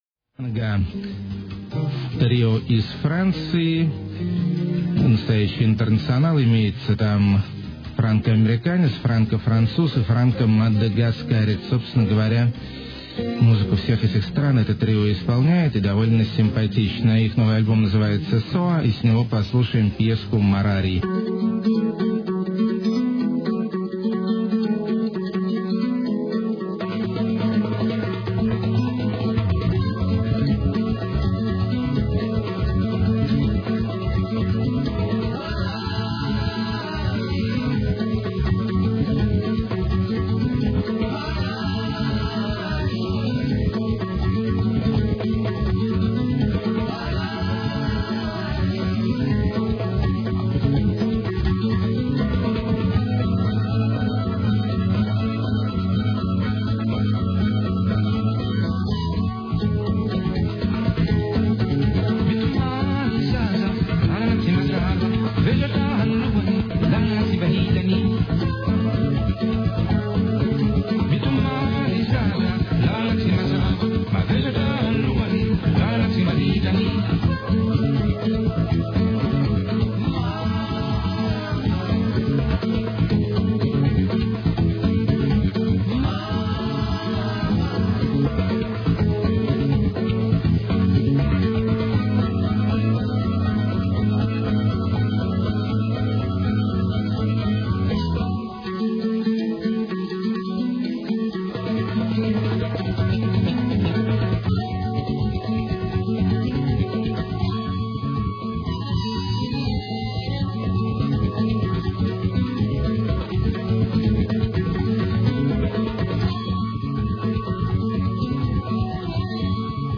mutant nordic jazz
creole chanson
electro-psyche-surf
60s retro pops
maximum drunk lo-fi
micro electronika with weird vocals
eclectic electro/jazz well played
dark ironic cabaret
sexy afro-funk
cynical sick techno
flamenco-punk
smart melodic downtempo